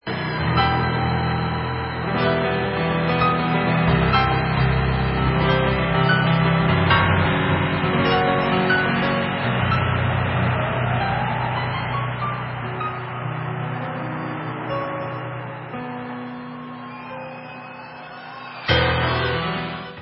Live From Le Zenith